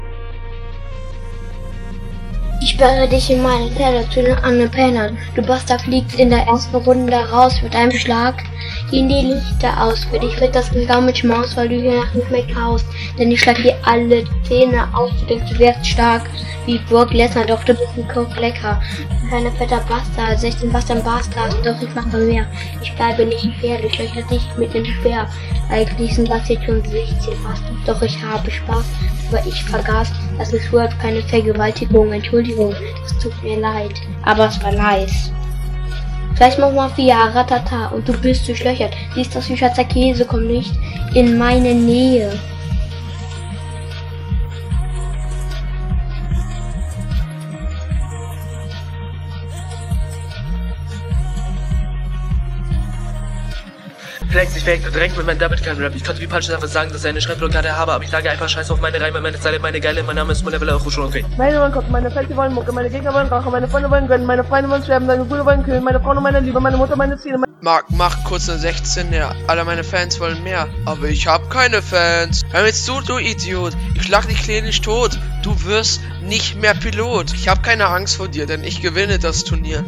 Ernsthaft? Irgendwie klingt das nicht nach Rap, sondern so, als würdest du einfach ablesen und …